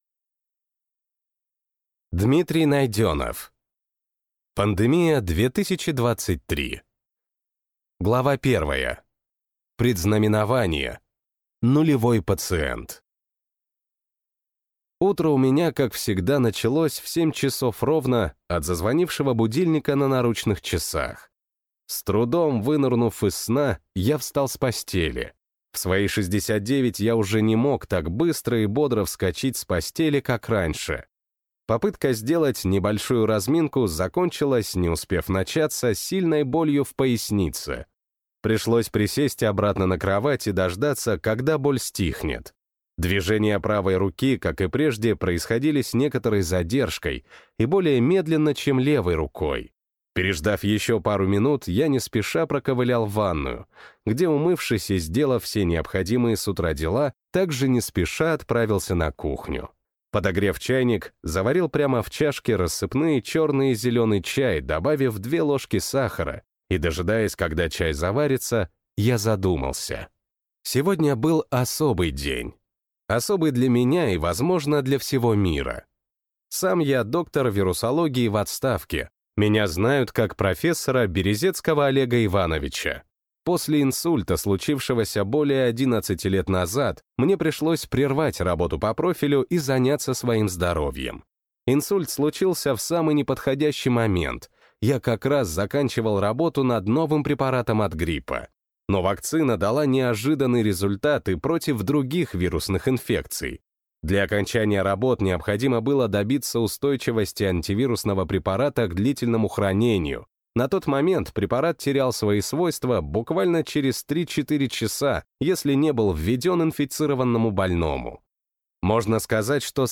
Аудиокнига Пандемия 2023 | Библиотека аудиокниг